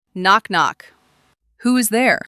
Bu gelişmiş metin-metinden-dialog sistem, yazılı senaryoları doğal, ifade dolu seslere dönüştürür ve birden fazla konuşmacı ile sunar.
elevenlabs-text-to-dialog-output.mp3